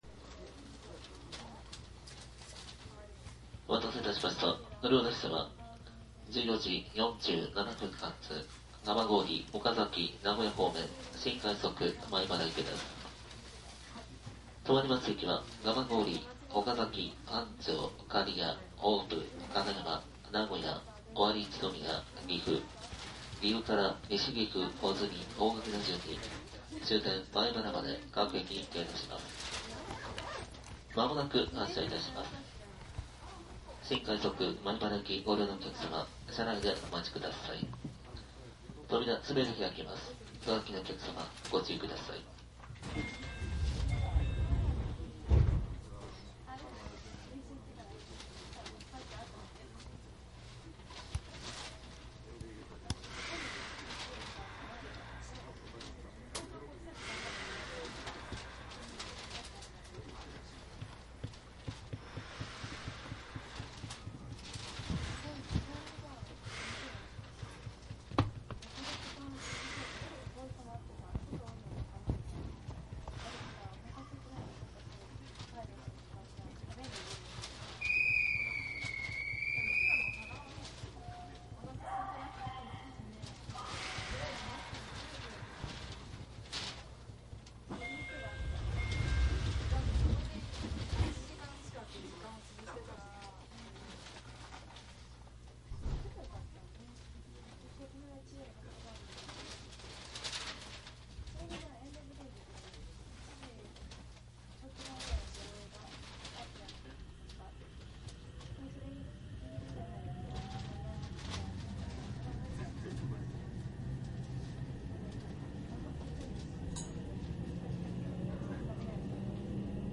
♪JR東海道線【313系】 走行音ＣＤ ♪ この区間はだいたい関西への移動ついでに録音していた事が多く、早朝に東京を出発すると昼ごろ名古屋に到着します。
客の声が大きく入るので何度か録音しましたが、それでもところどころ声が聞こえます。313系自体のモーター音が小さいので録音レベルの設定に神経を使います。
マスター音源はデジタル44.1kHz16ビット（マイクＥＣＭ959）で、これを編集ソフトでＣＤに焼いたものです。